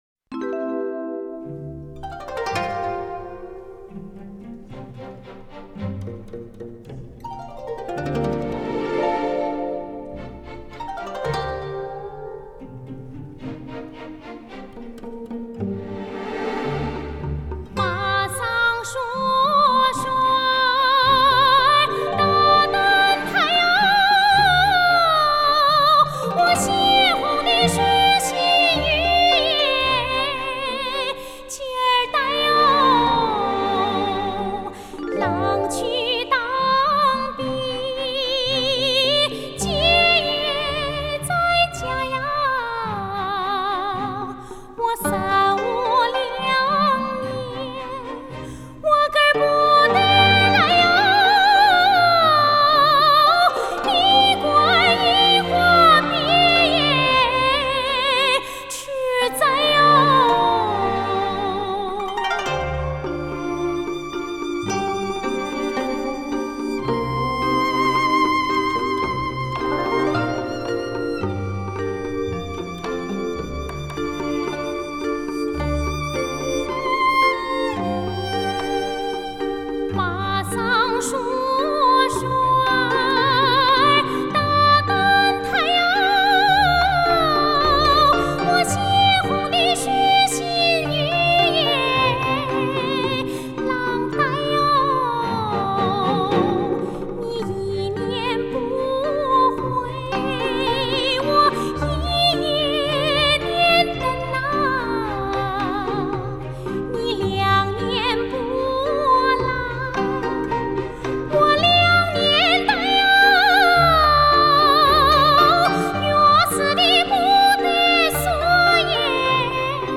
从曲调看，它是建立在五声羽调式上的民歌。整段歌共五句，句与句之间连环相扣的旋律进行就像缠绵不断的情丝从心底流出，表现了夫妻两个忠贞不渝的纯洁爱情。